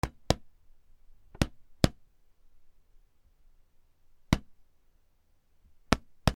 椅子の表面を軽く叩く
/ J｜フォーリー(布ずれ・動作) / J-22 ｜椅子
『パンパン』